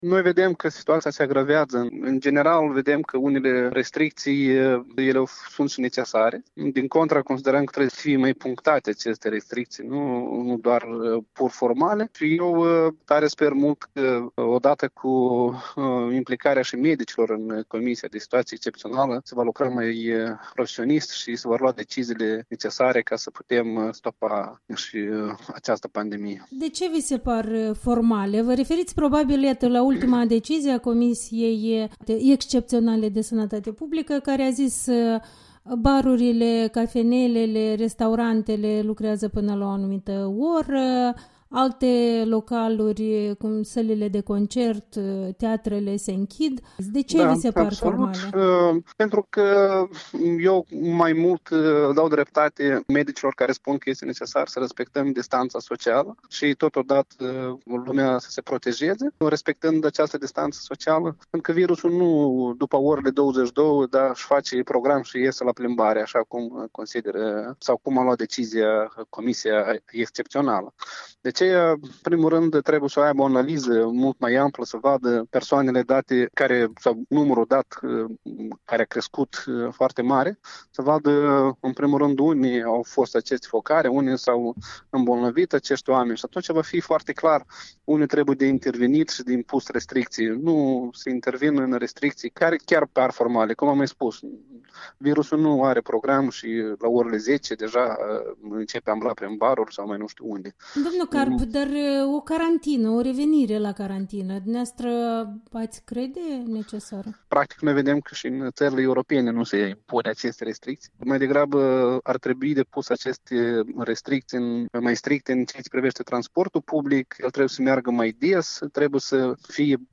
Interviul dimineții la EL: cu Lilian Carp